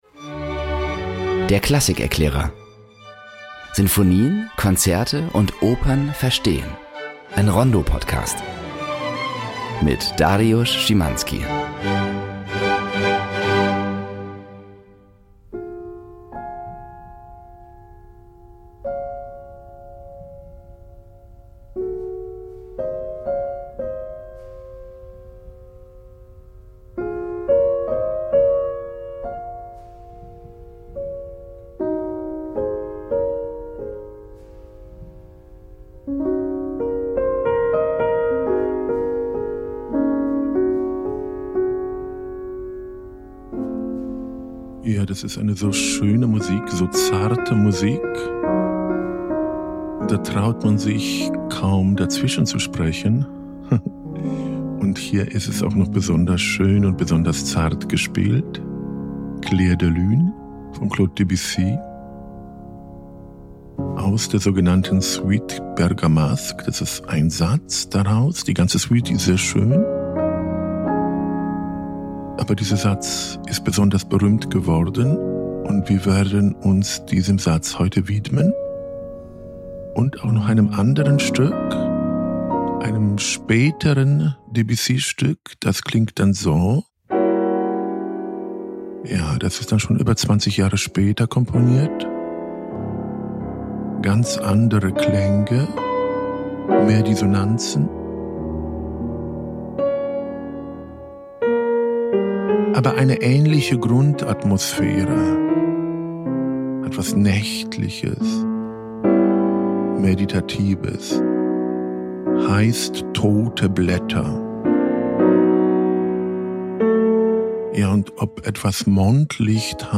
Zwischendurch fallen Blätter; der Mond leuchtet; Klaviermusik aus der Werbung erklingt.